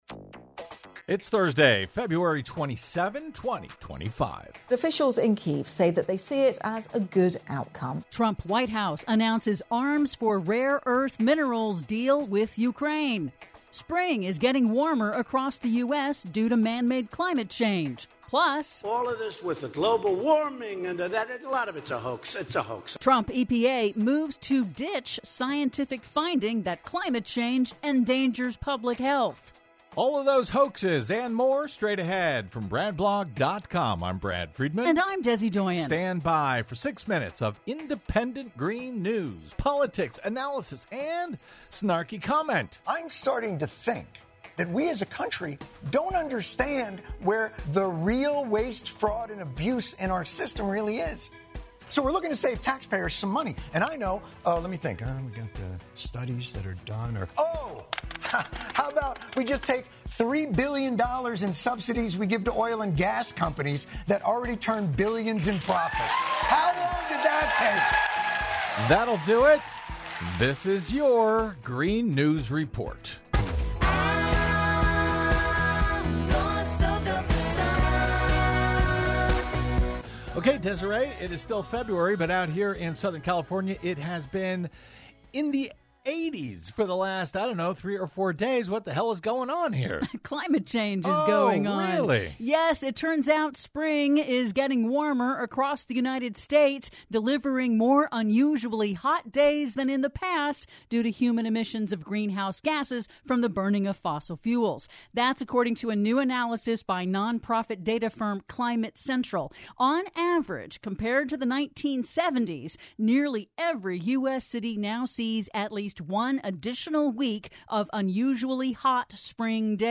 IN TODAY'S RADIO REPORT: Trump White House announces arms-for-rare-earths deal with Ukraine; Spring is getting warmer across the U.S. due to man-made climate change; PLUS: Trump E.P.A. moves to ditch scientific finding that climate change endangers public health... All that and more in today's Green News Report!